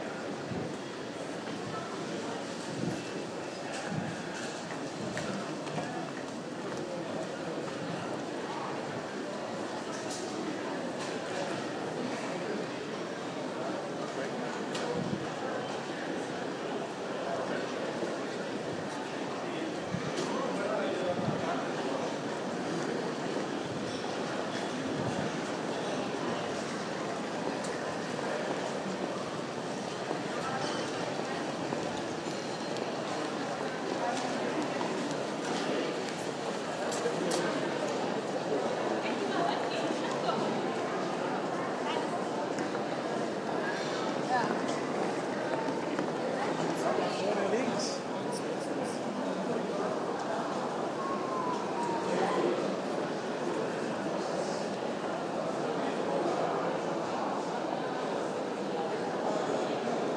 Berlin Tegel sound